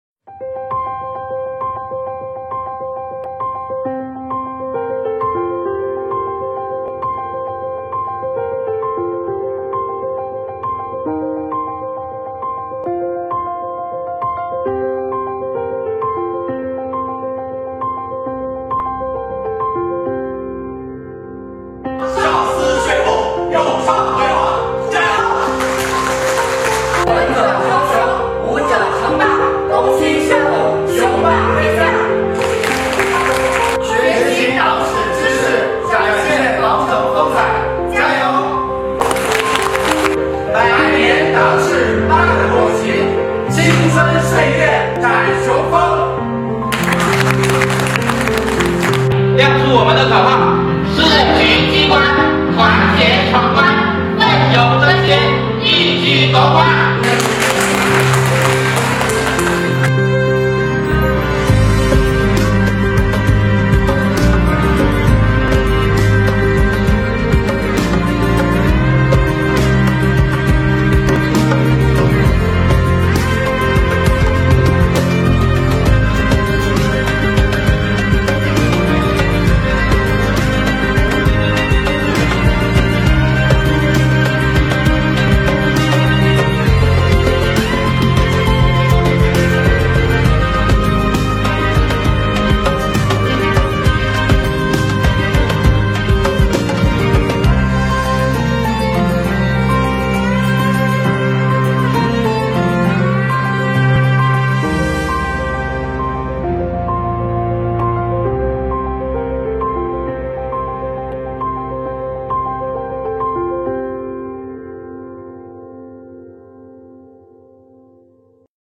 快来围观~党史知识竞赛比赛现场↑↑↑